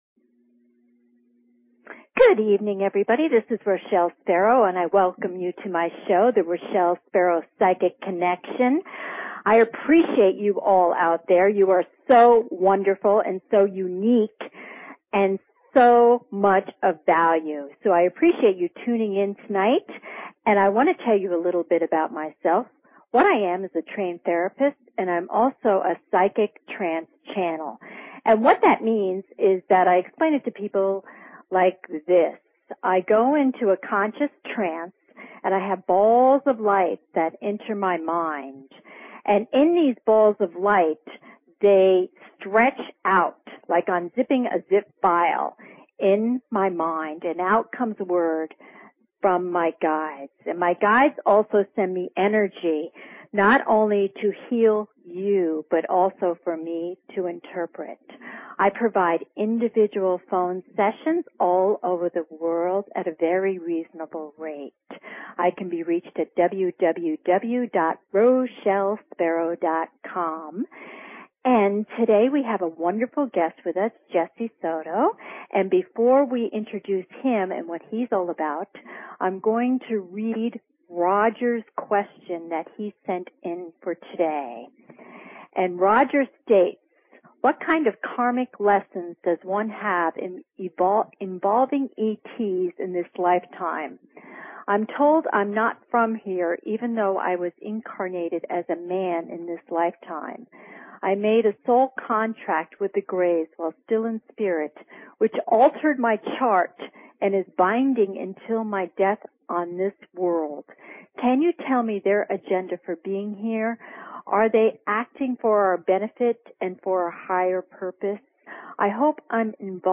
Talk Show Episode, Audio Podcast, Psychic_Connection and Courtesy of BBS Radio on , show guests , about , categorized as